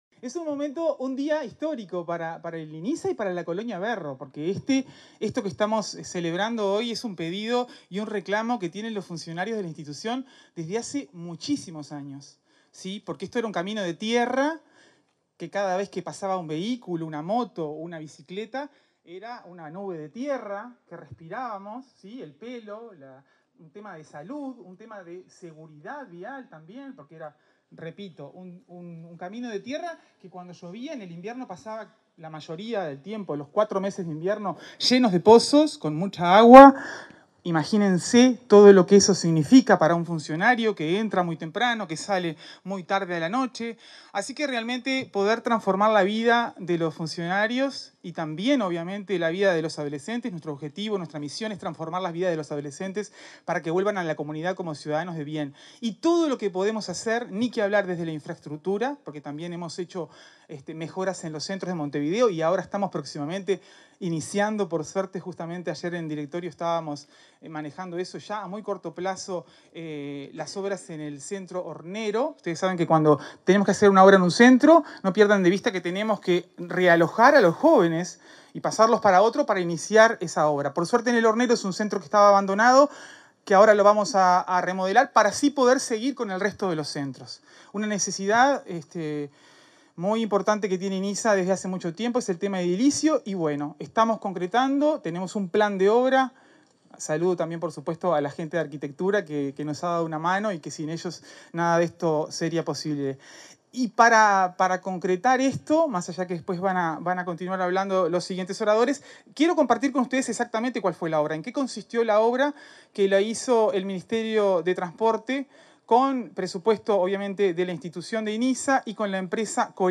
Palabras del ministro de Transporte y de la presidenta del Inisa
En el marco de la inauguración de la pavimentación de la caminería de la Colonia Berro, este 29 de marzo, se expresaron la presidenta del Instituto